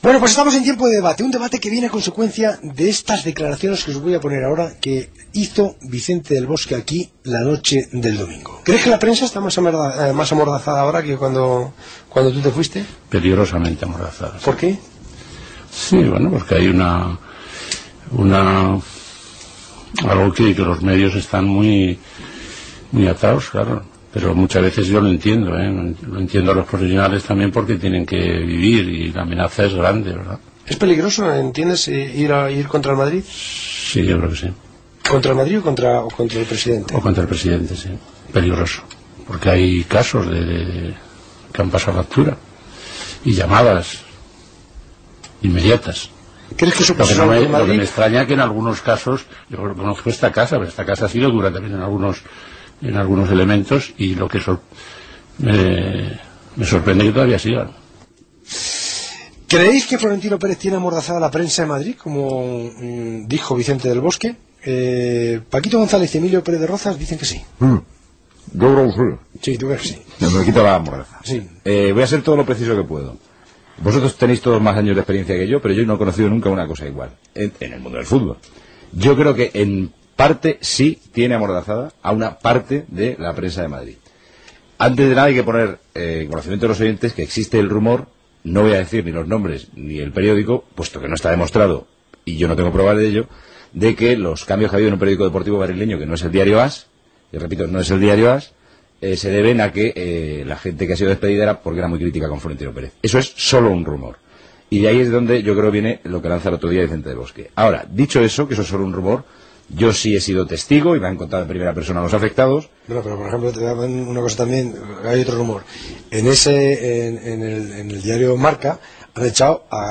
Missatges de l'audiència Gènere radiofònic Esportiu